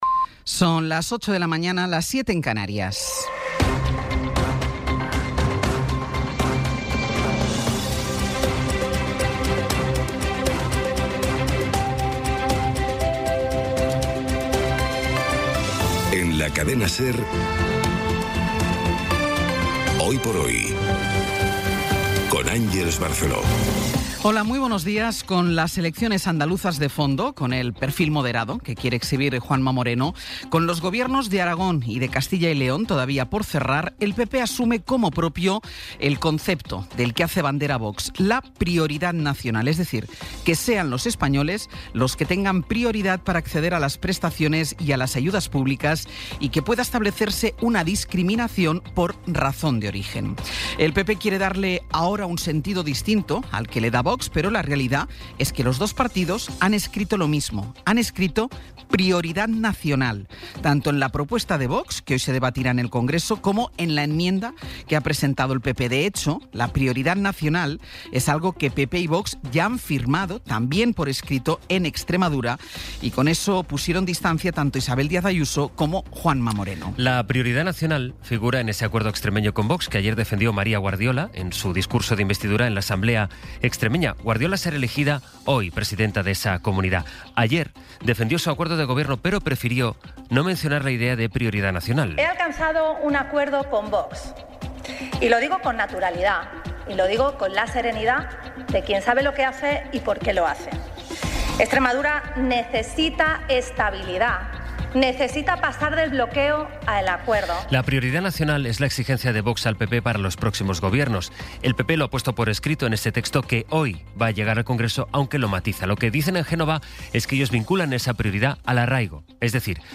Las noticias de las 08:00 20:03 SER Podcast Resumen informativo con las noticias más destacadas del 22 de abril de 2026 a las ocho de la mañana.